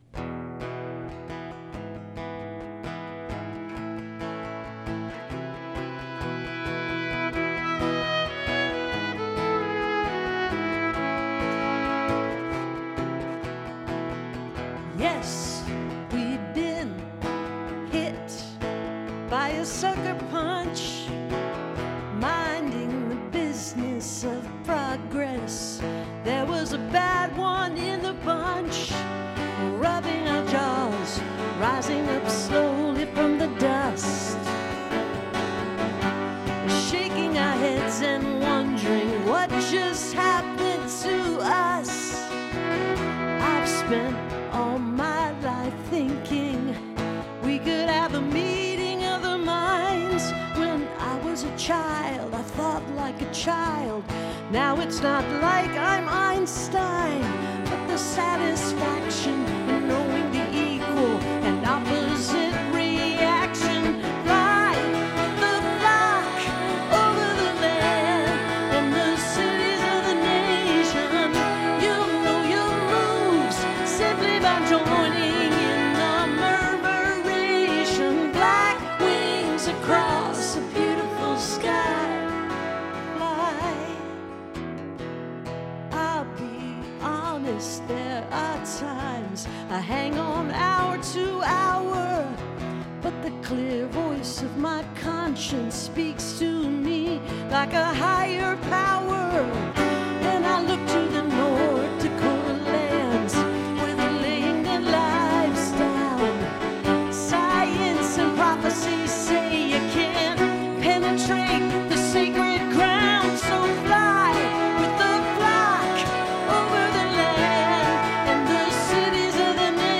(captured from an online radio broadcast)